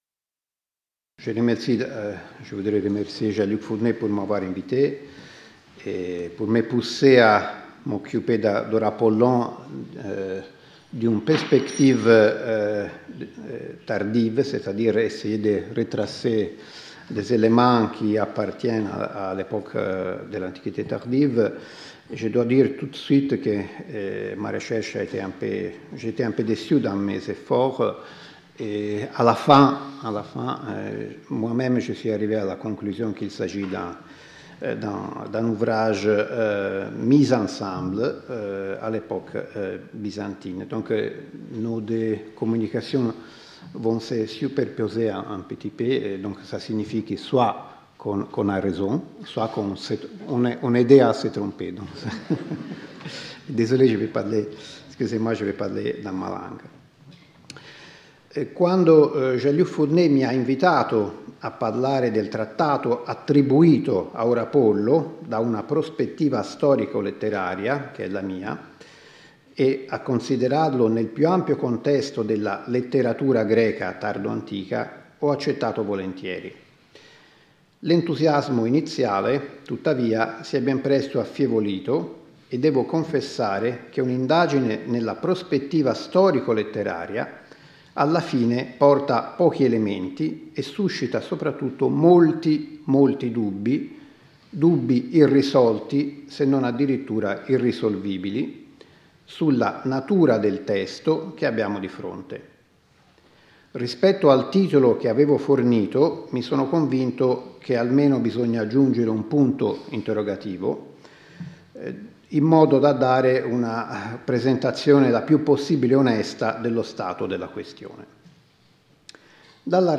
Symposium